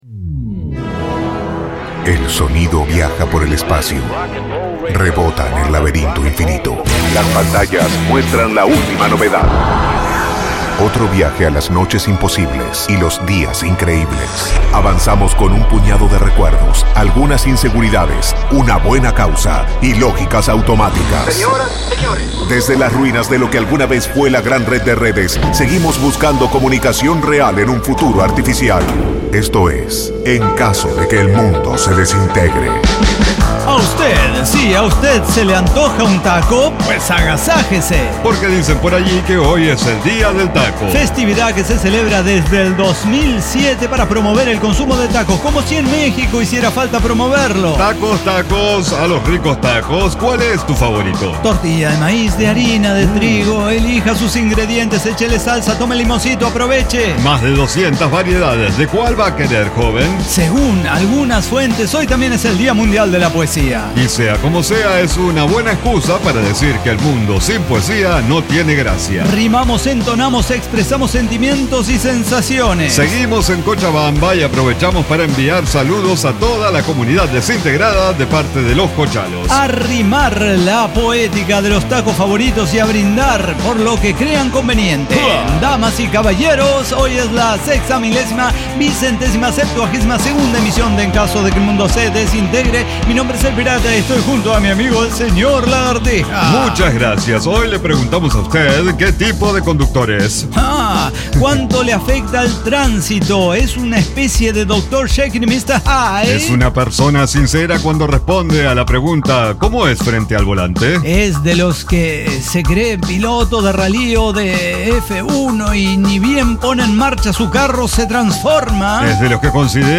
Diseño, guionado, música, edición y voces son de nuestra completa intervención humana.